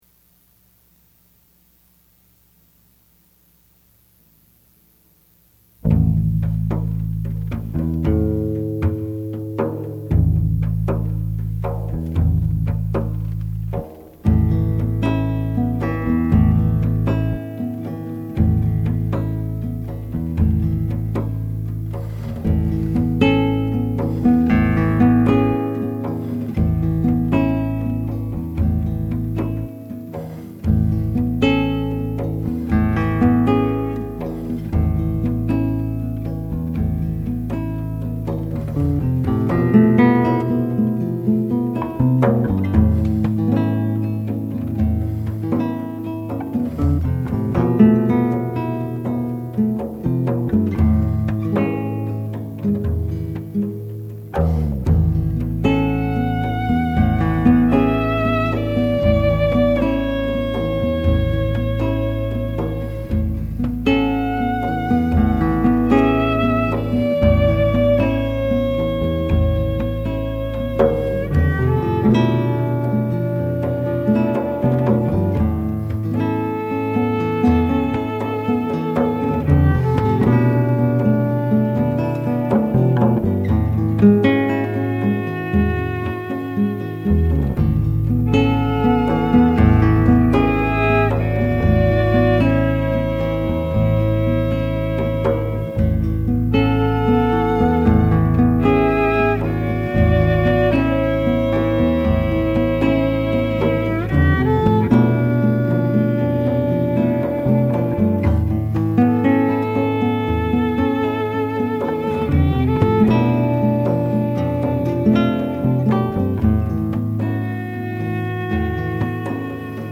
flamenco guitarist
and fuses a jazz sensibility with flamenco rhythms.
electric bass